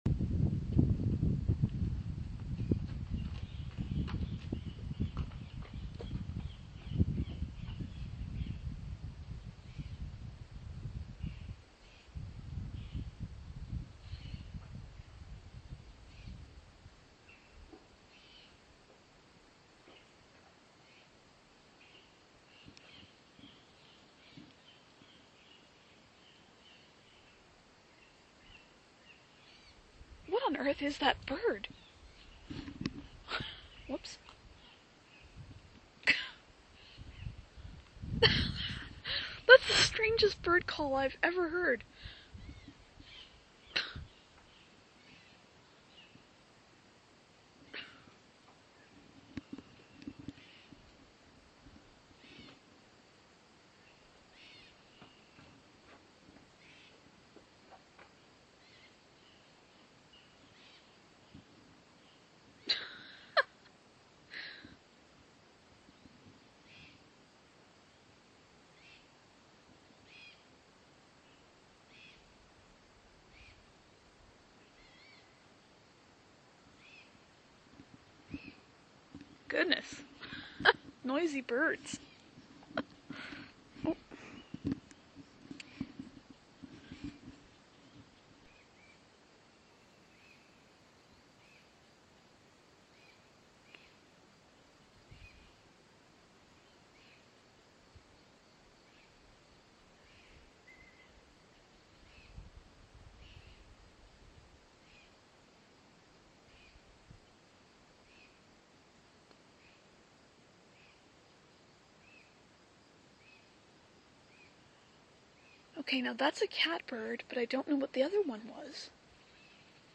Some Very Strange birds
I caught a conversation this morning between two birds, one of which is a catbird. I haven't the faintest idea what the other is.